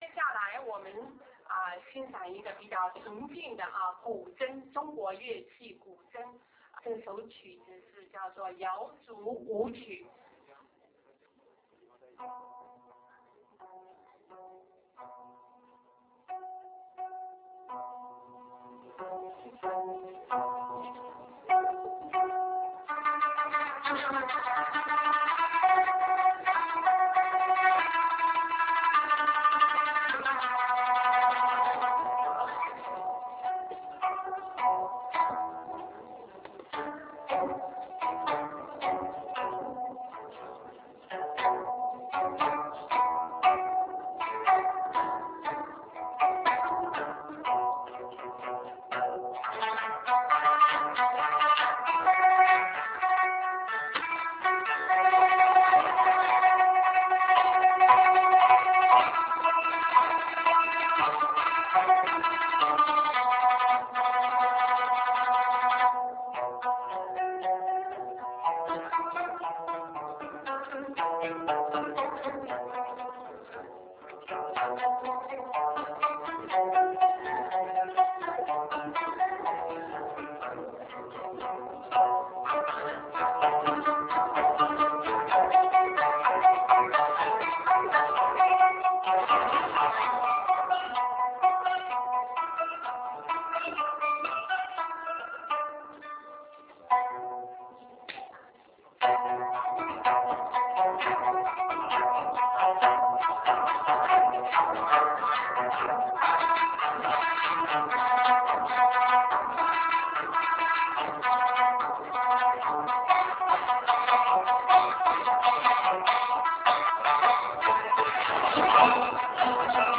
GuZhengQu_YaoZuWuQu_56k.ra